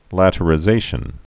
(lătə-rĭ-zāshən)